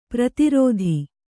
♪ prati rōdhi